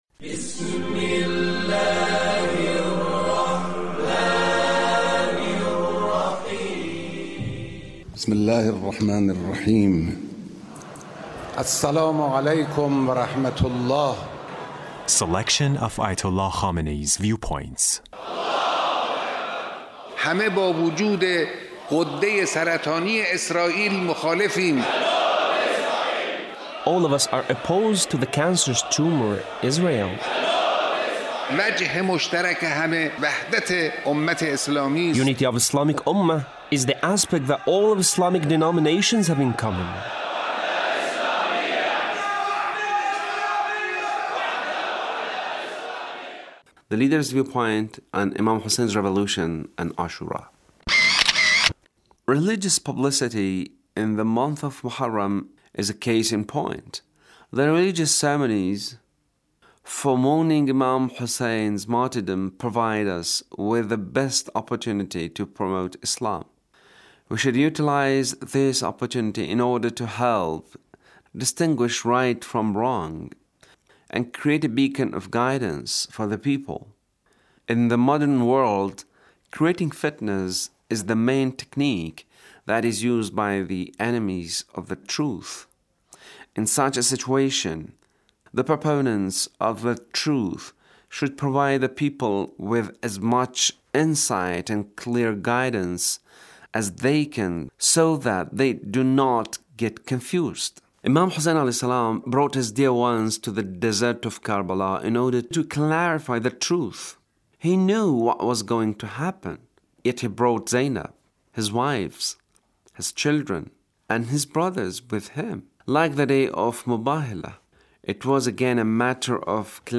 Leader's speech (1476)